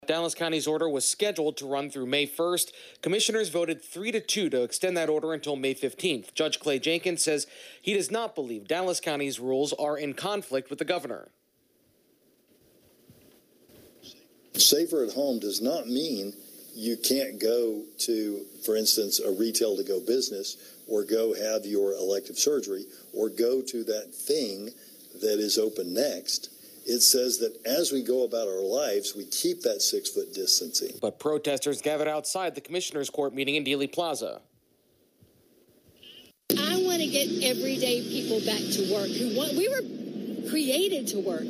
And because I’m no expert in pressing buttons, our producer has asked if my feed dropped out because there was such a large gap between my voice and the soundbite.